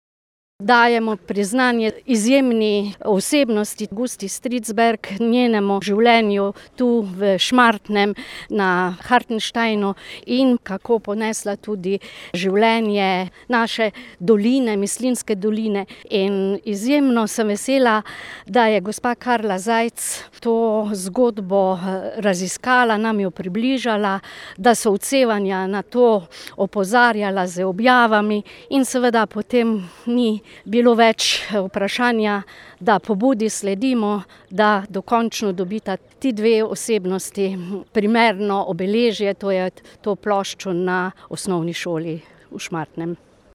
Velika podpornica vspostavitve spominskega obeležja, podžupanja Mestne občine Slovenj Gradec Martina Šisernik: